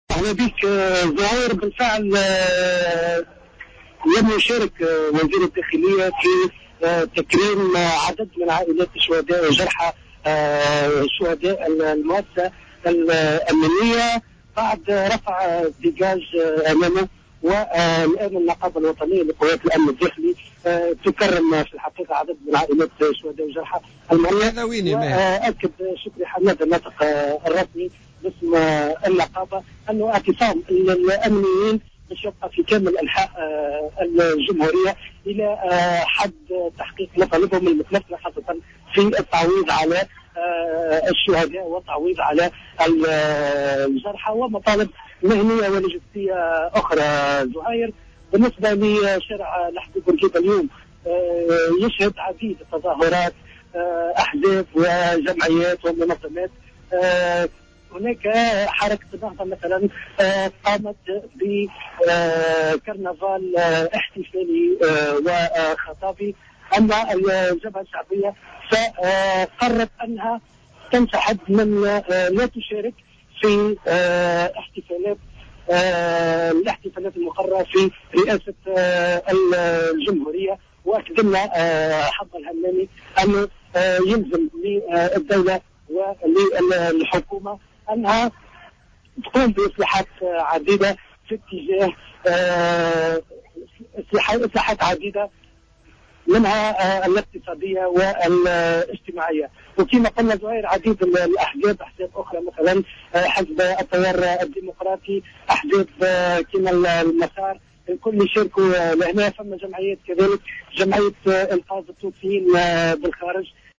Notre correspondant t√©l√©charger partager sur